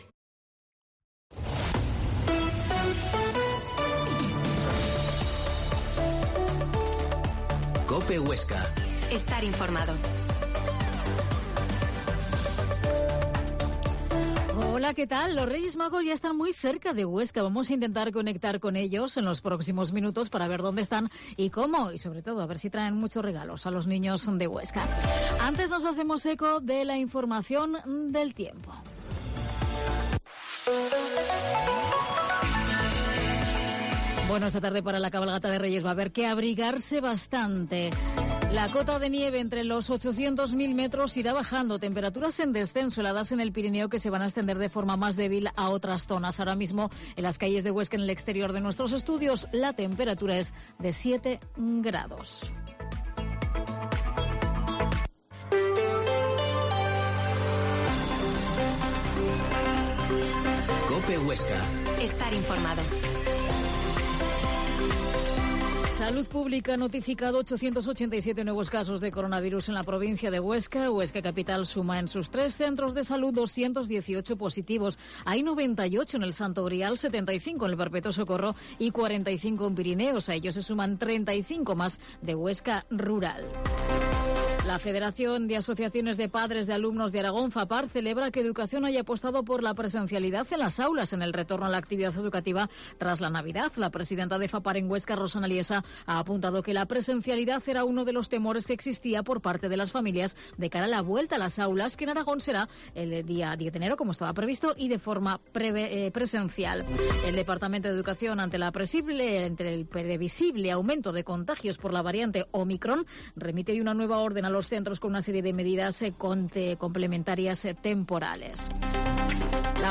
Mediodía en Cope Huesca 13,50h. Entrevista al Rey Melchor